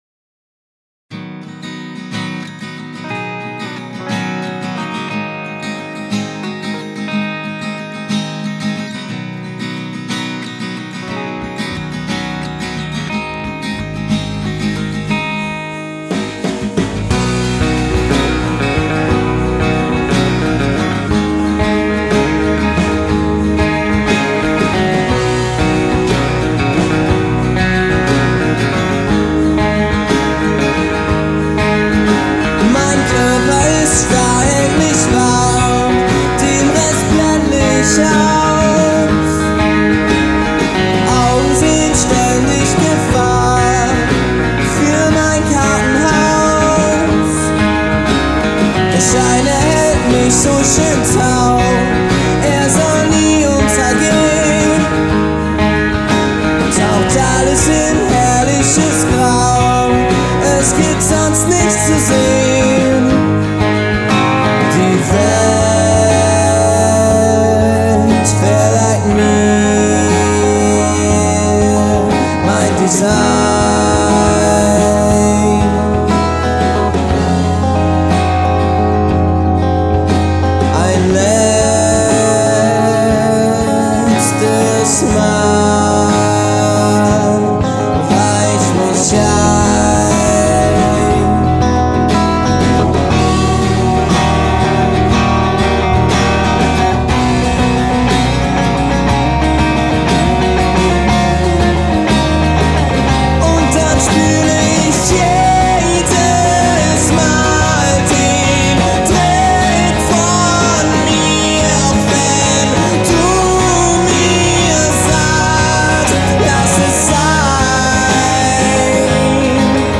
ich hab schon wieder dieses overcompressed Feeling .. bedanke mich für konstruktive Kritik und Vorschläge zur Verbesserung im Voraus. 47144